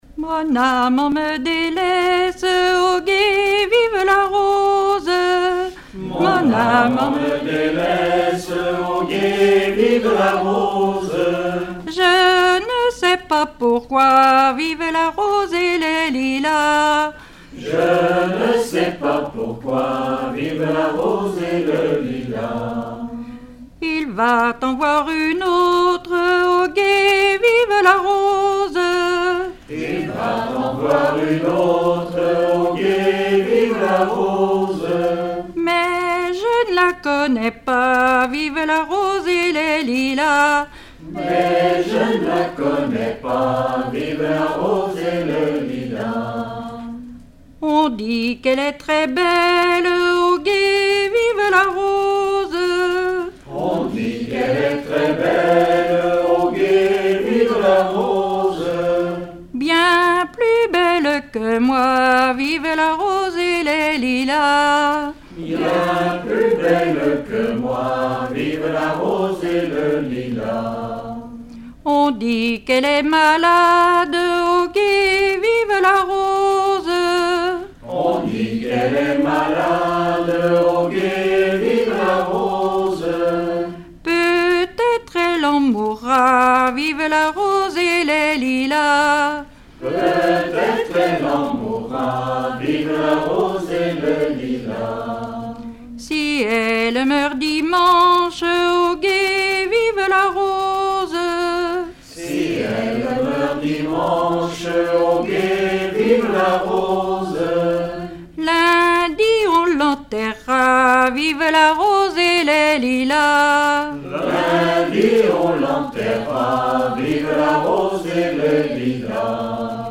Genre laisse
veillée (2ème prise de son)
Pièce musicale inédite